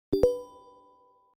select.mp3